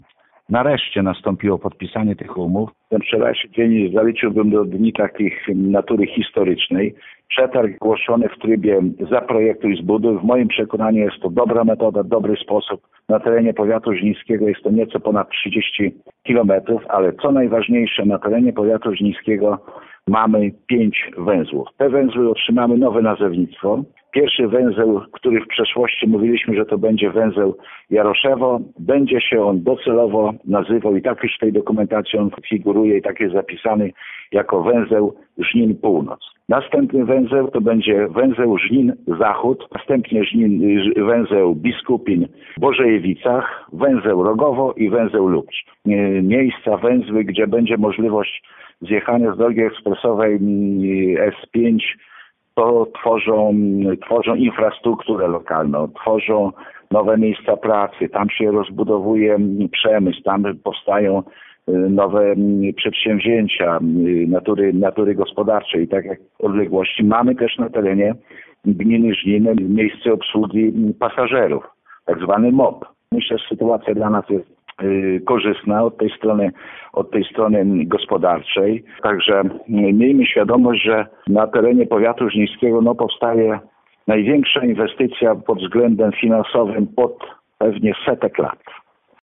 To jednak inwestycja z przyszłością, która wypłynie na rozwój gospodarczy Żnina, mówi starosta żniński Zbigniew Jaszczuk, który uczestniczył we wczorajszym spotkaniu.